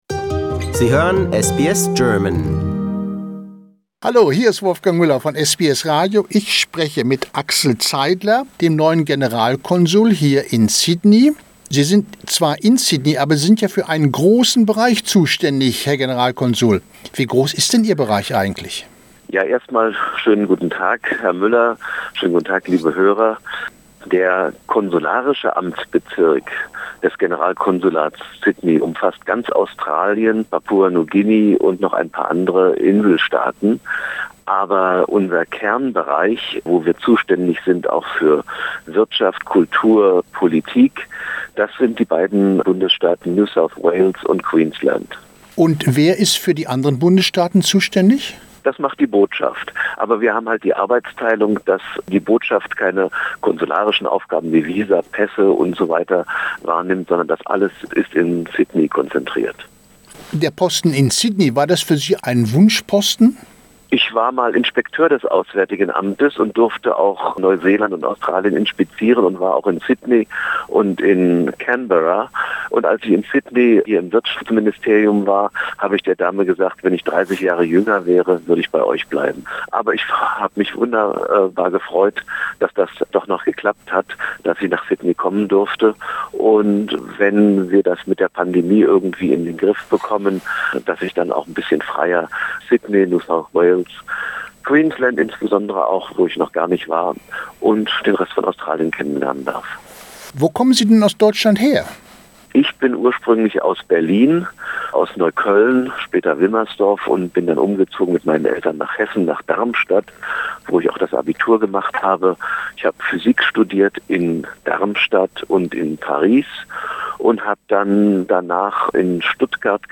Was bedeutet den Deutschen dieser Tag und warum ist es der 3. Oktober? Diese Frage beantwortet Axel Zeidler, neuer Generalkonsul in Sydney.